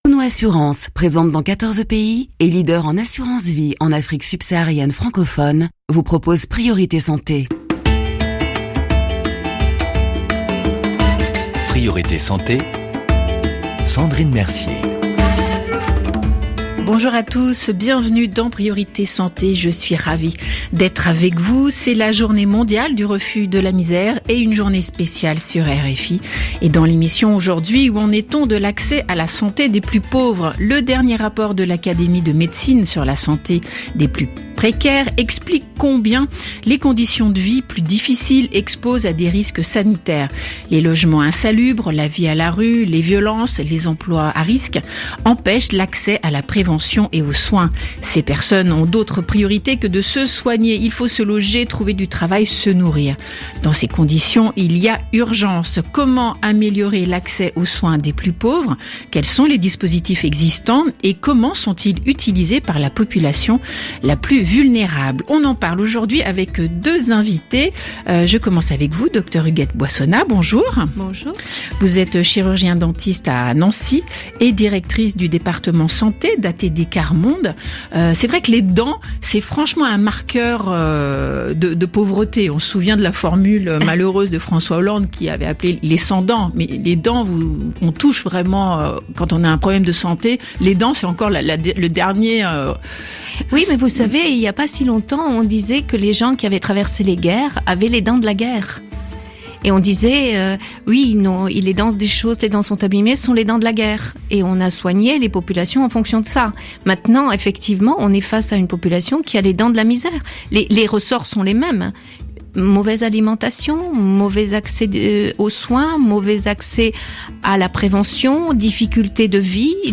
En compagnie de trois invités